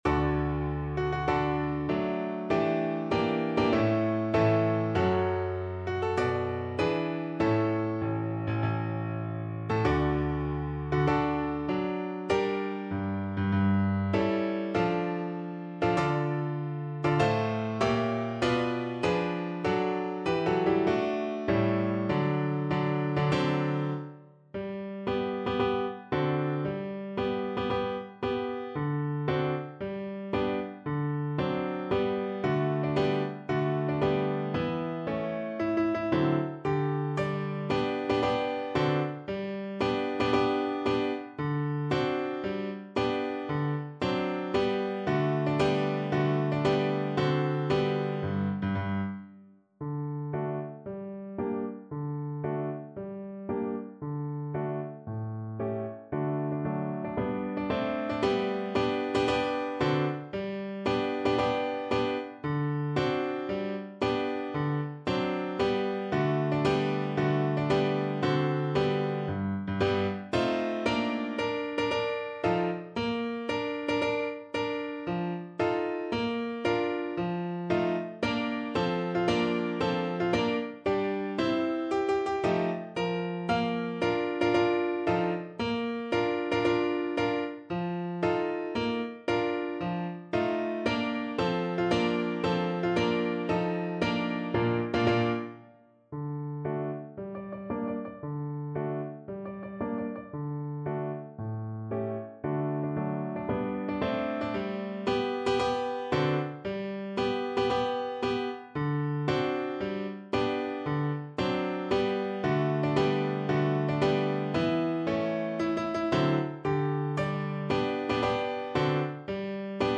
Verdi: Marsz triumfalny (na skrzypce i fortepian)
Symulacja akompaniamentu